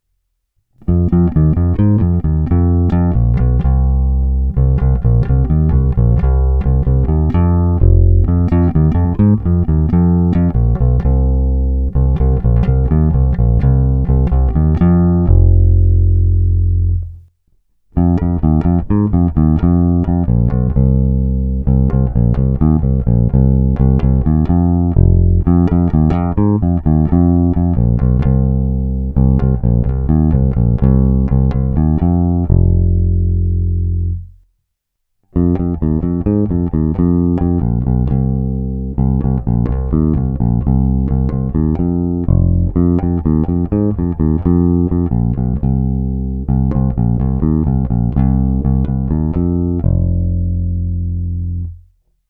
Krásně vyrovnaný zvuk mezi jednotlivými strunami.
Není-li uvedeno jinak, jsou provedeny rovnou do zvukové karty a jen normalizovány, s plně otevřenou tónovou clonou.